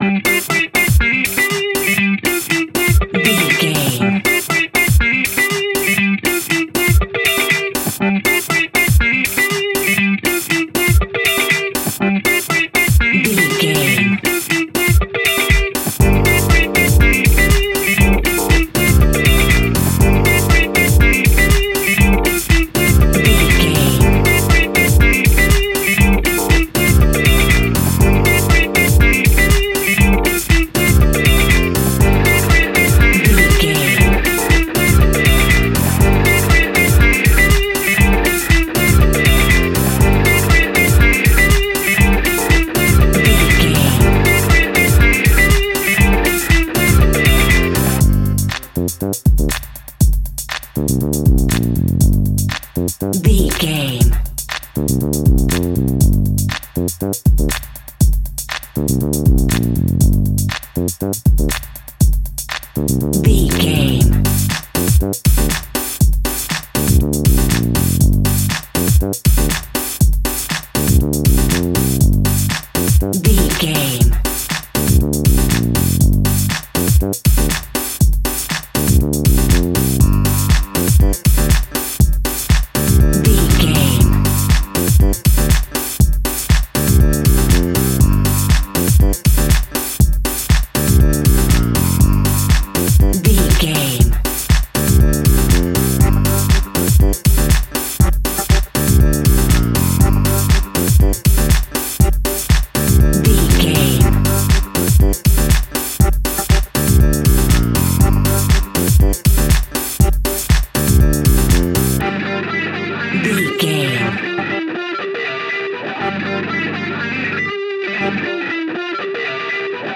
Aeolian/Minor
energetic
uplifting
hypnotic
groovy
drums
bass guitar
electric guitar
electric piano
disco house
electronic funk
upbeat
synth leads
Synth Pads
synth bass
drum machines